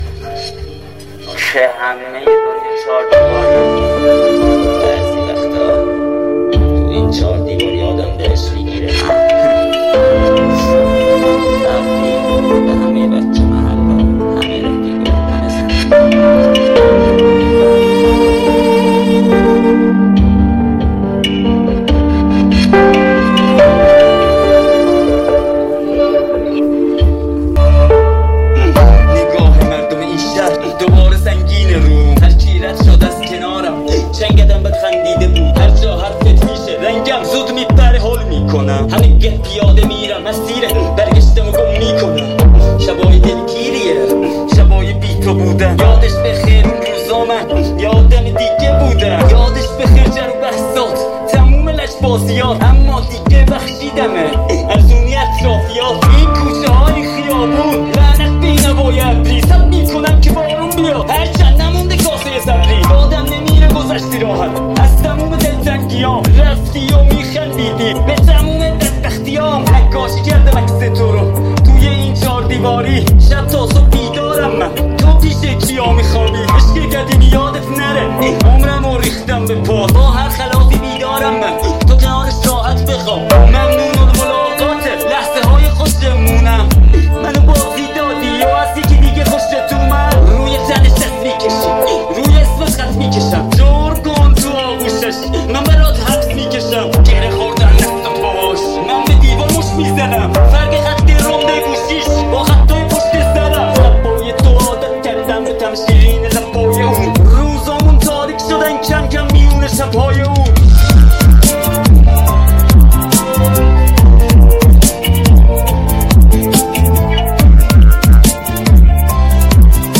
Genre rap